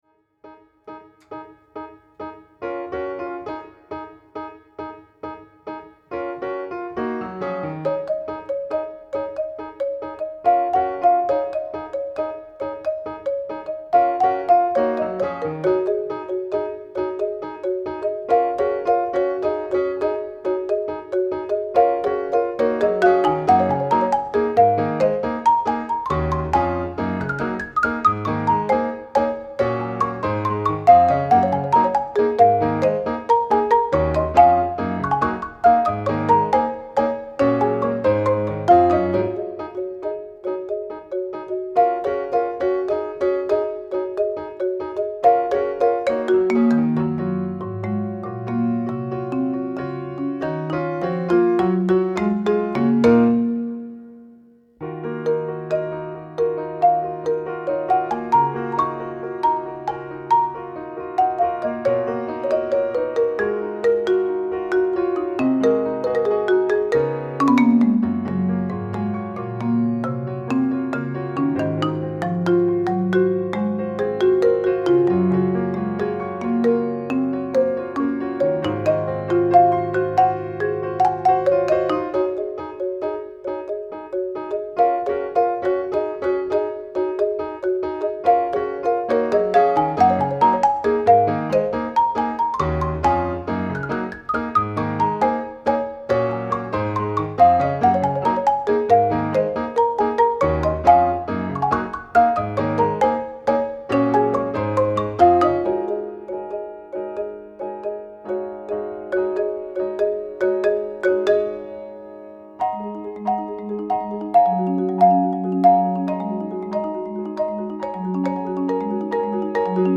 Sehr rhythmisch und groovy Schwierigkeitsgrad